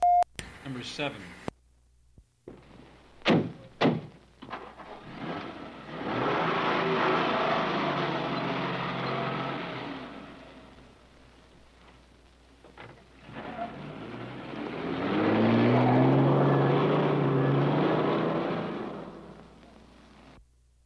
老式汽车 " G1318 大发动机空转
描述：汽车发动机怠速不均匀，但稳定而且不会改变。低沉，低沉的隆隆声。 这些是20世纪30年代和20世纪30年代原始硝酸盐光学好莱坞声音效果的高质量副本。 40年代，在20世纪70年代早期转移到全轨磁带。我已将它们数字化以便保存，但它们尚未恢复并且有一些噪音。
标签： 汽车 运输 光学 经典
声道立体声